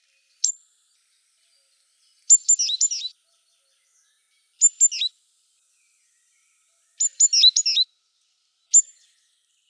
Звуки синицы
Позыв синички